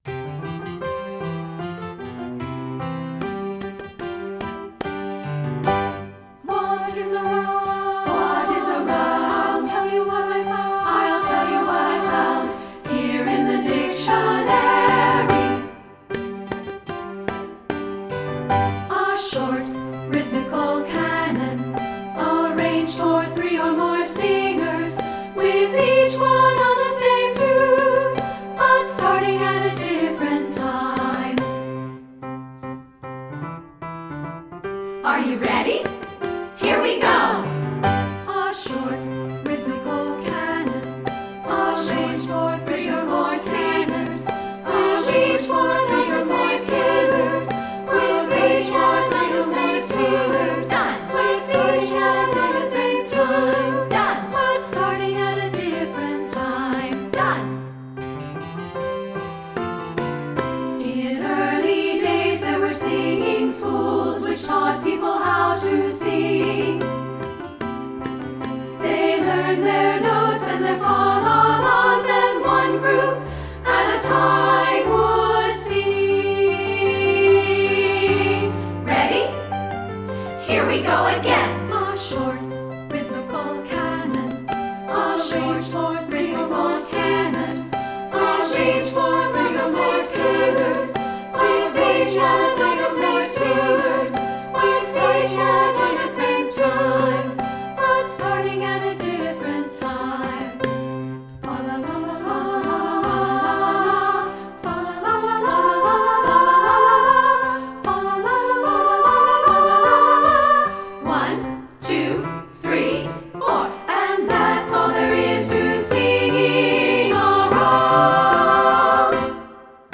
secular choral
3-part, sample